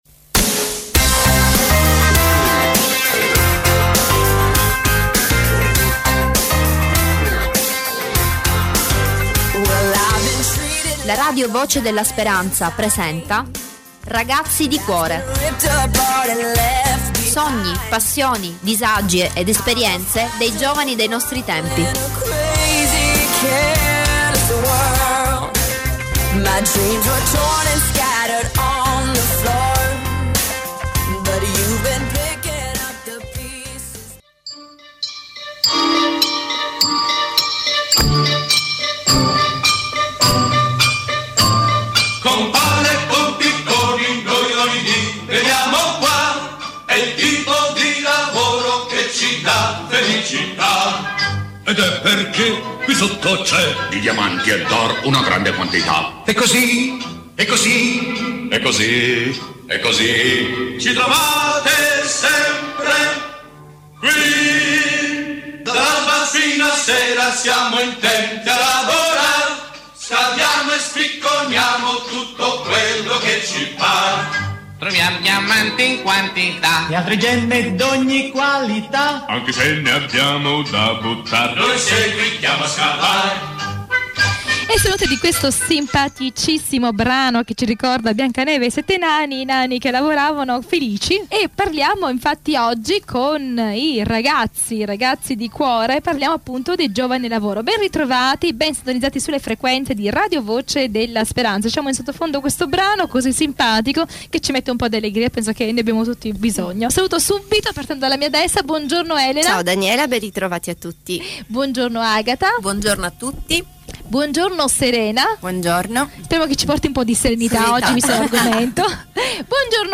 Vari ospiti in studio.